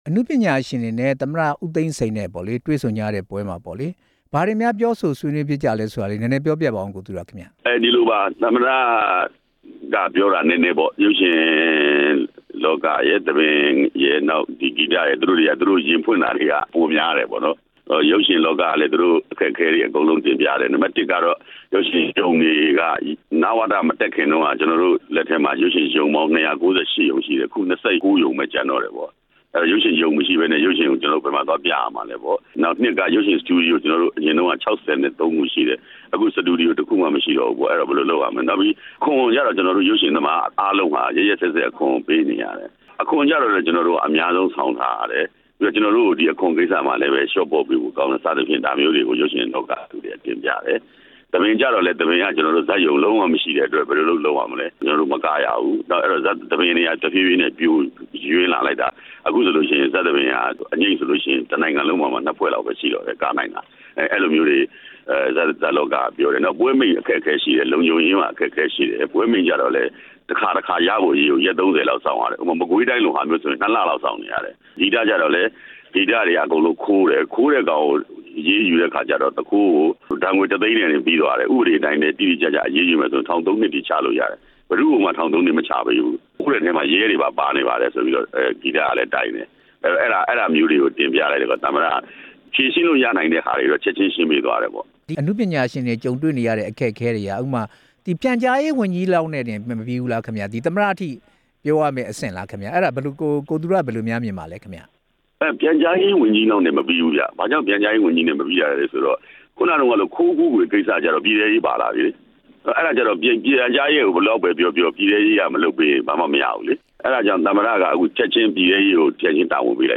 ကိုဇာဂနာနဲ့ မေးမြန်းချက်